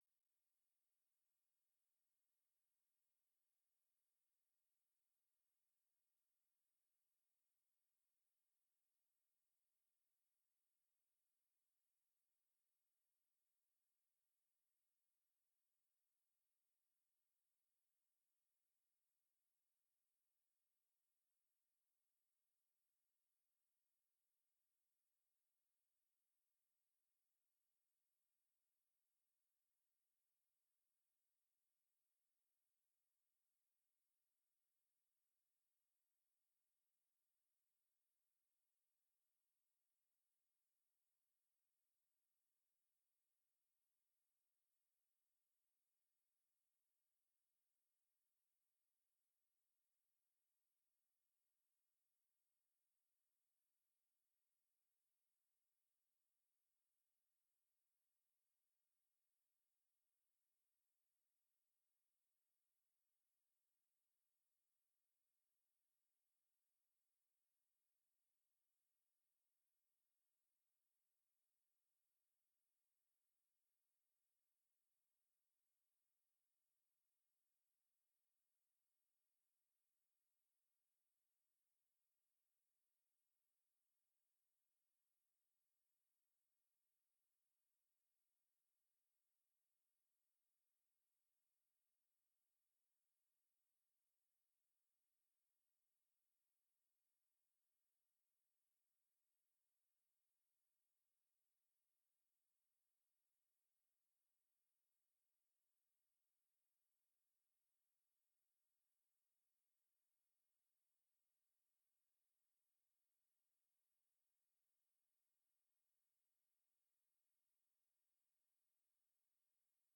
Audio recording of the 10am service, 4th Sunday sermon